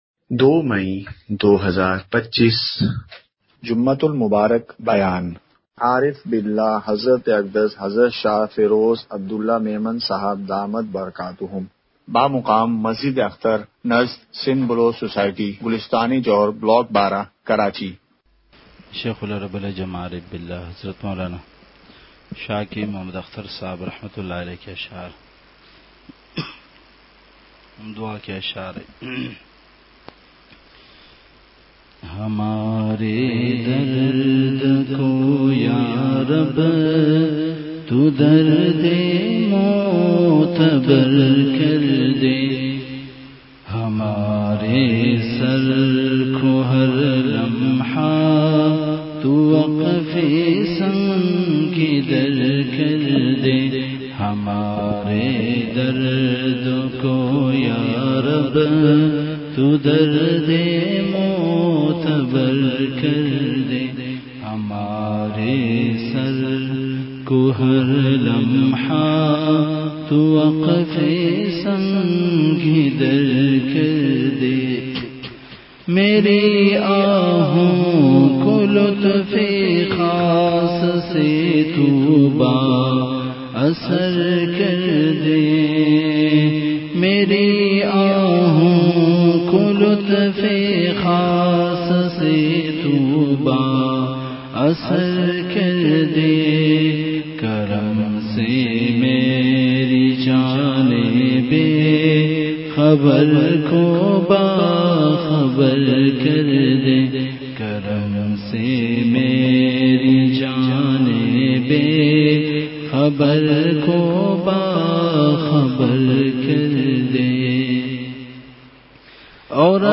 Deeni Sfr Sindh Isha 25 Nov 25 Dard Bhara Bayan Jamai Masjid Husain Bin Ali Tando Bago